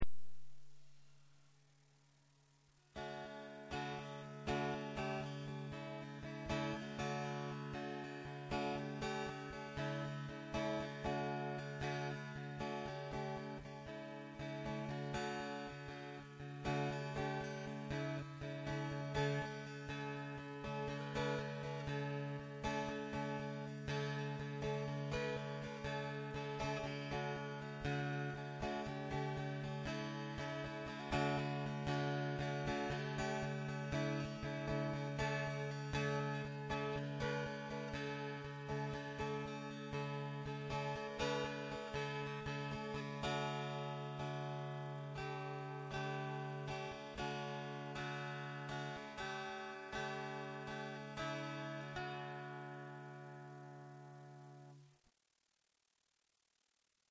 le corps est en sapelli et la « table » en cerisier ondé.
dulcimer-sauvage.mp3